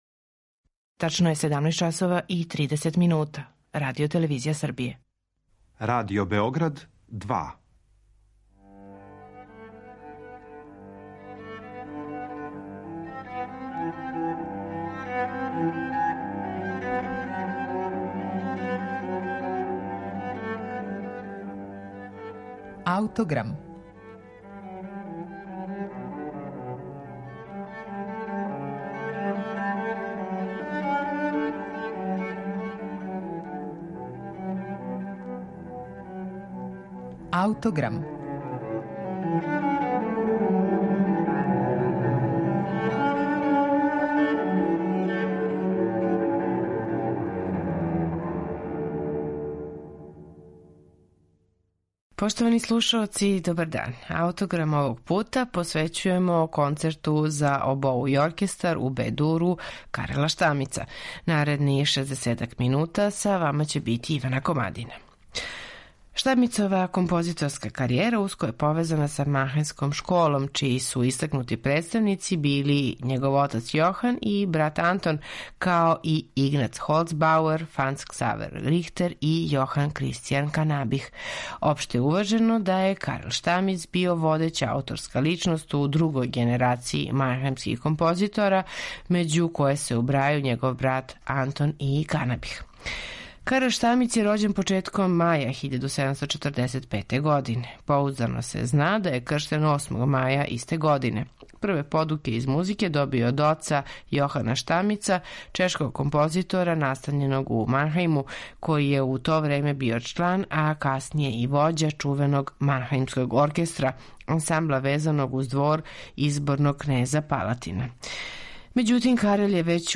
Kонцерт за обоу Kарла Штамица
Kонцерт за обоу и оркестар у Бе-дуру Kарела Штамица, коме смо посветили данашњи Аутограм, једно је од дела које красе све битне одлике манхајмске школе.
У три става овог концерта, срећемо многе изненадне промене динамике, чувени мајнахајмски парни ваљак са карактеристичном остинатном линијом баса; мајнхајмски уздах, поступак позајмљен из барокне праксе, у коме се акценат ставља на први од два тона у силазном низу. Срећемо и чувену манхајмску ракету: брзи узлазни разложени акорд, као и манхајмске птице са имитацијом птичјег пева у високим регистрима.